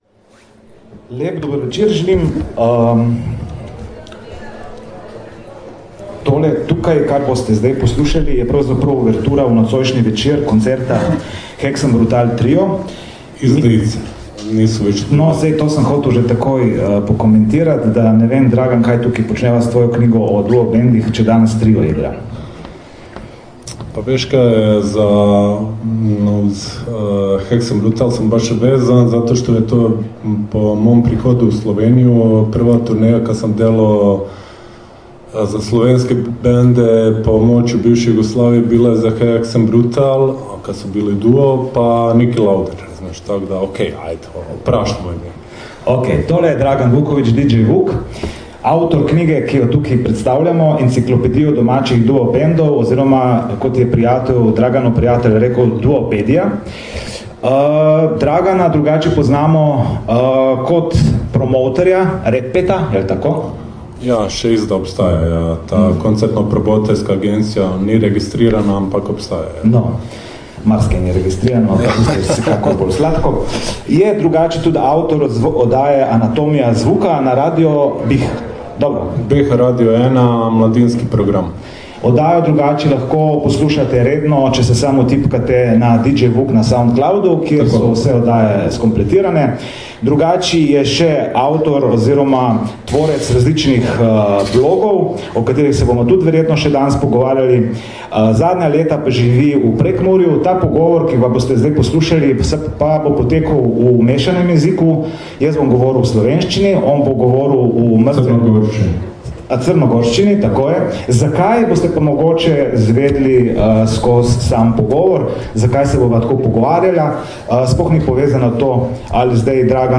Posnetek pogovora
v Komuni Kina Šiška pred koncertom Hexenbrutal Tria.